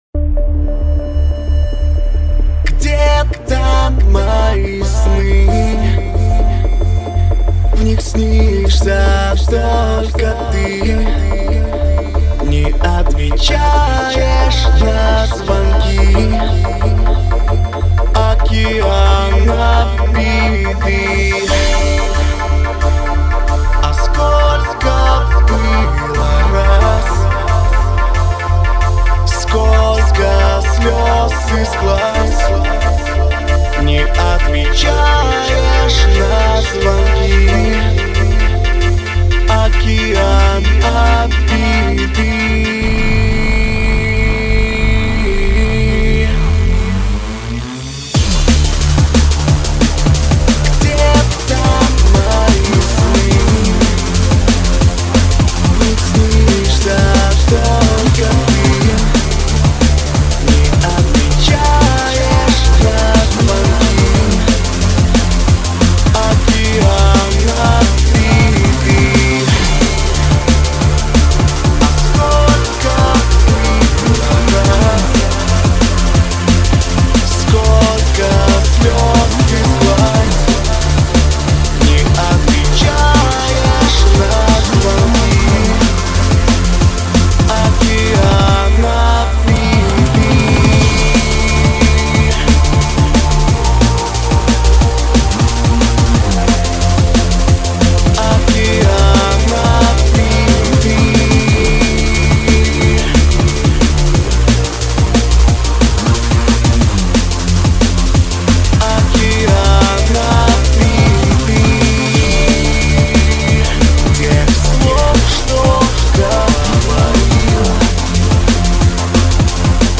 dnb_dm_n_bass_edit_mp3CC_biz.mp3